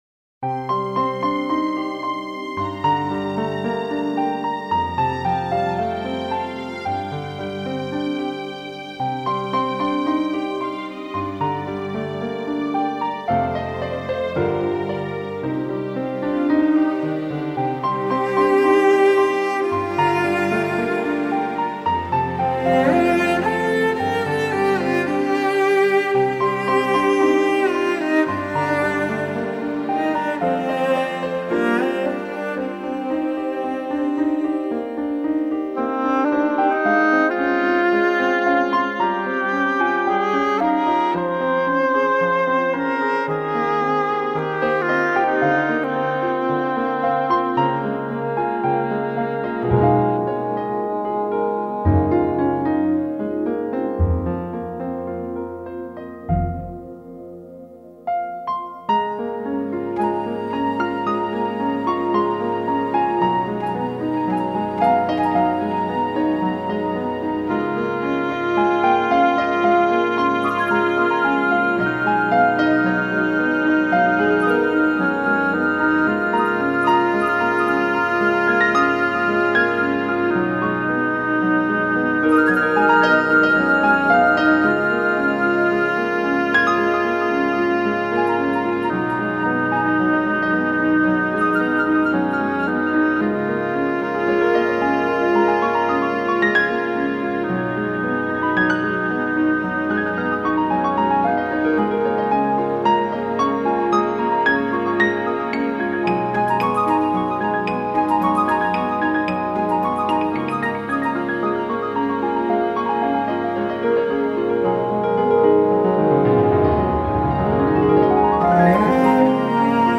悠扬的钢琴声轻轻响起